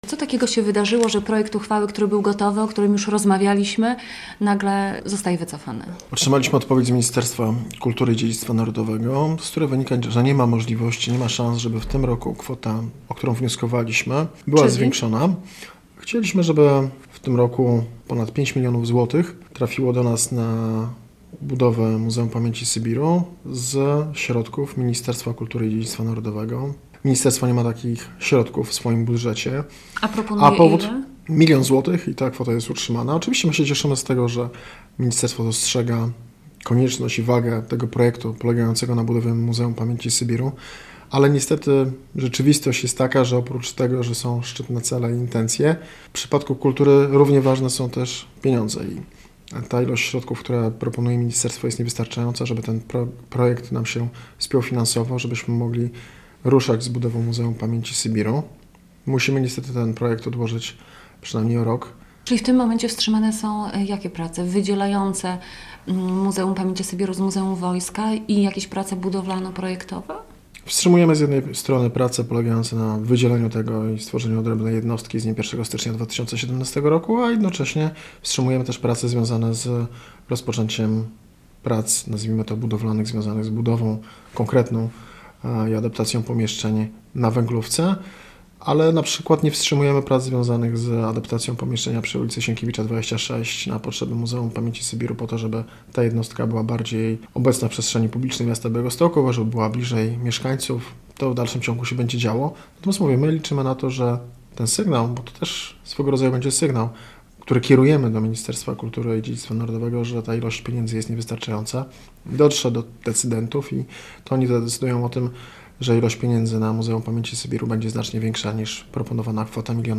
Radio Białystok | Gość | Rafał Rudnicki - zastępca prezydenta odpowiedzialny za kulturę